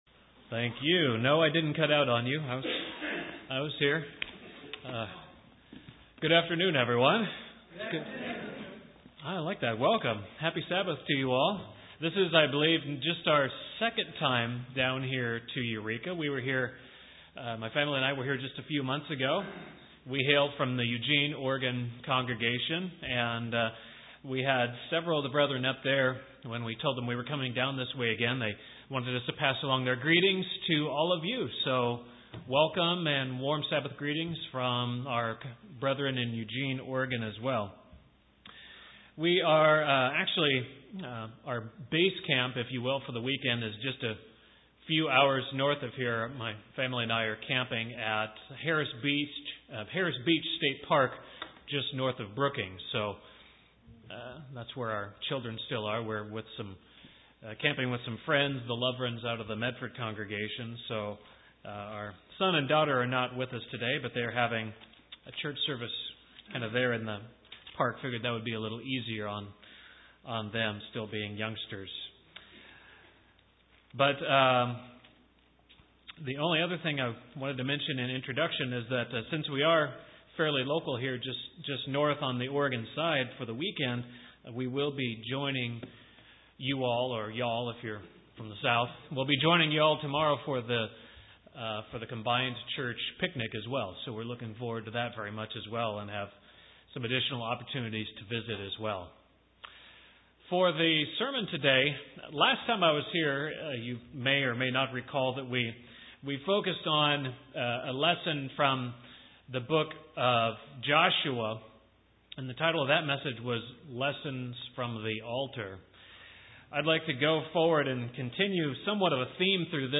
The book of Judges covers a period of some 325 years of history. This sermon examines some of the lessons and characteristics of this time in Israel's history.